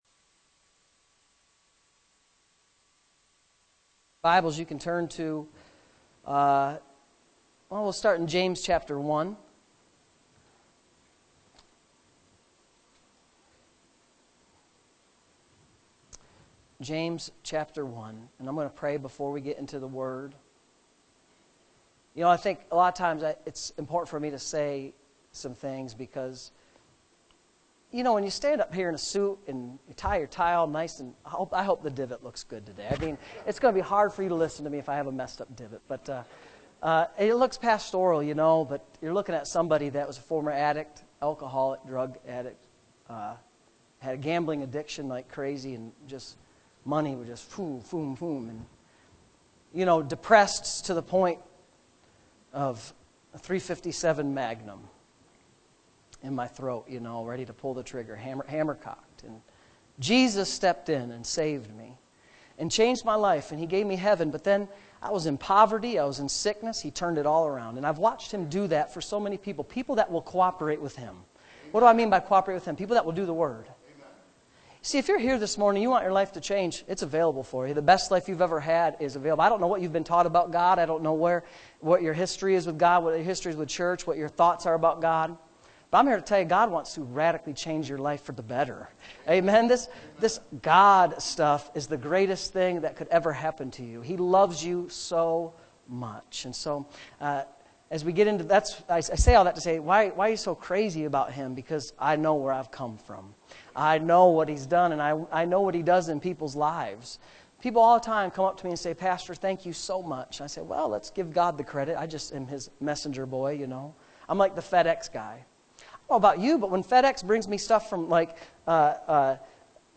Sunday Morning Services